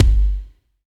88 KICK 2.wav